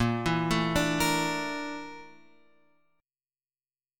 A# Major 7th Flat 5th